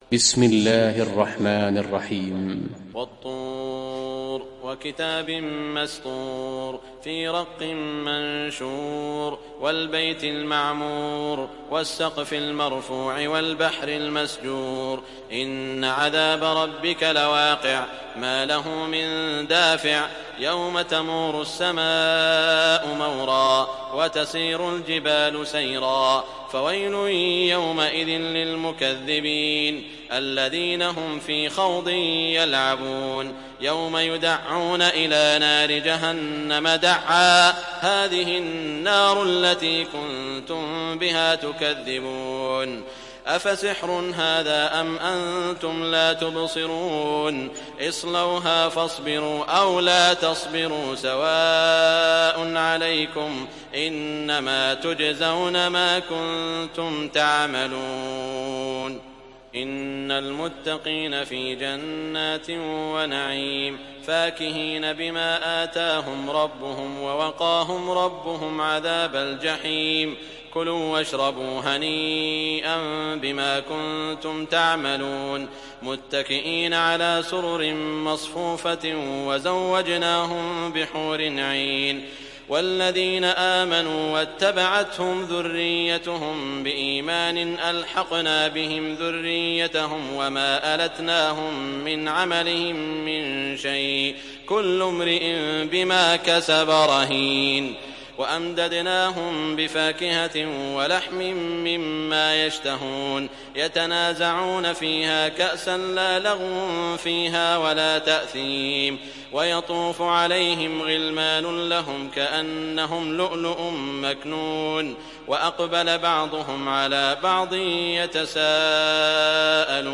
Surat At Tur Download mp3 Saud Al Shuraim Riwayat Hafs dari Asim, Download Quran dan mendengarkan mp3 tautan langsung penuh